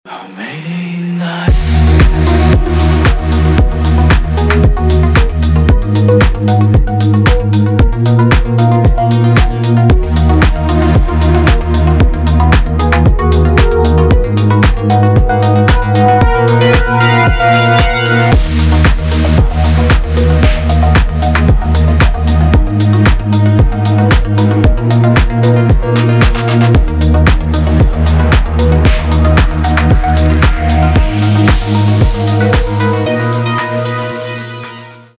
• Качество: 320, Stereo
deep house
атмосферные
кайфовые
ремиксы